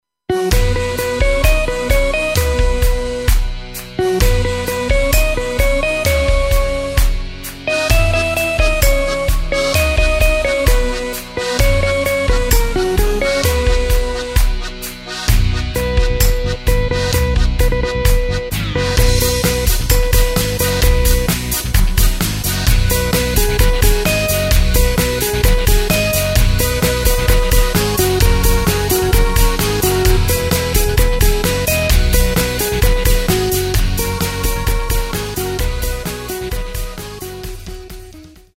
Takt:          4/4
Tempo:         130.00
Tonart:            C
Party-Hit aus dem Jahr 2024!
Playback mp3 Demo